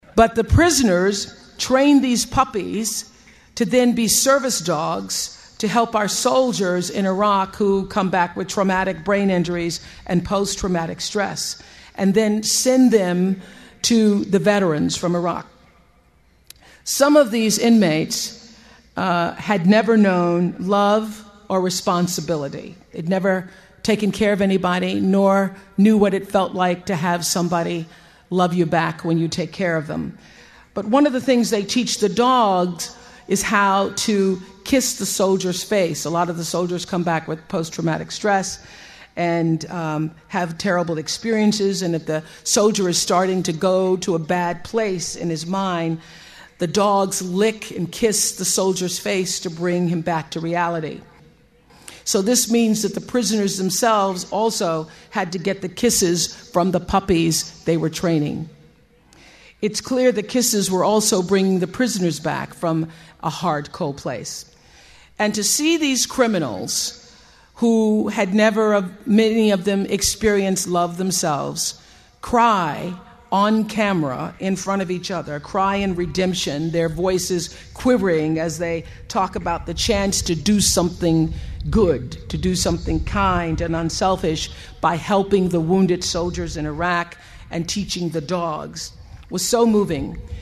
借音频听演讲，感受现场的气氛，聆听名人之声，感悟世界级人物送给大学毕业生的成功忠告。